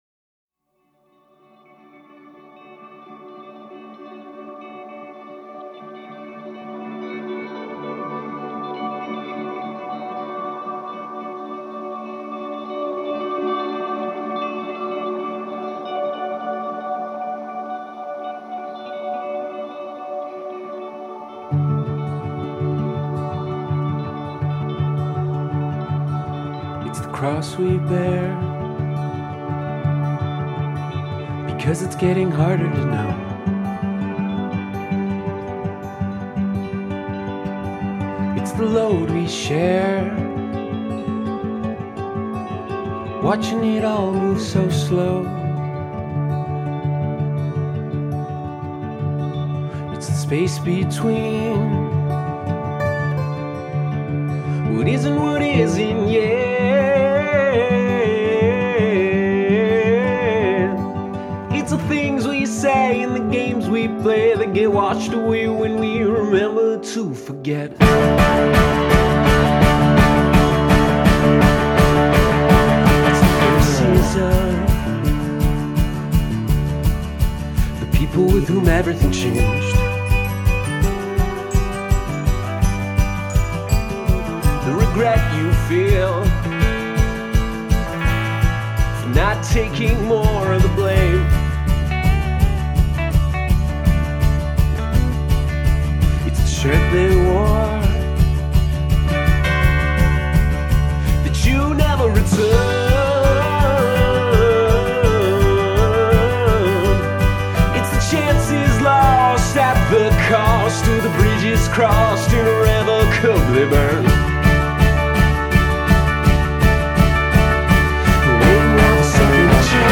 Melodic indie-folk sextet